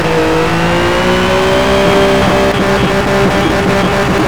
Index of /server/sound/vehicles/lwcars/porsche_911_rsr